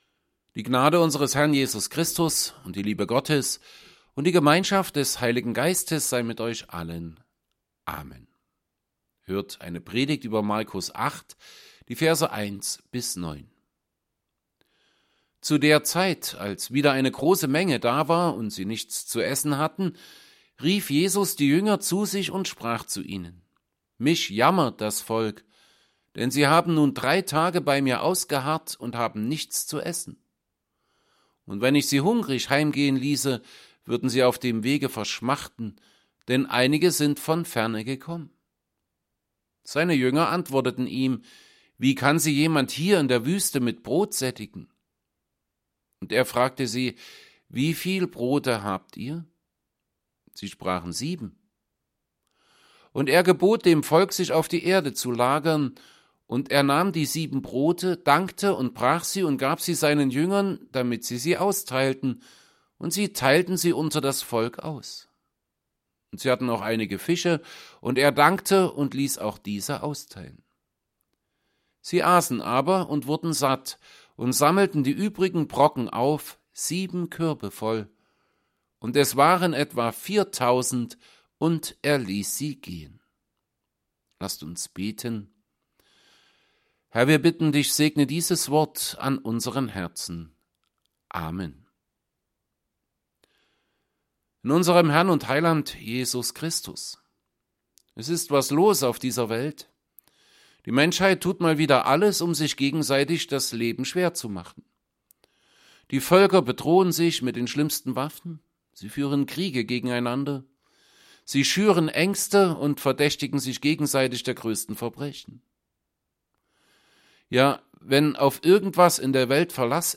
Predigt_zu_Markus_8_1b9.mp3